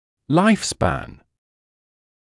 [laɪf spæn][лайф спэн]продолжительность жизни; срок службы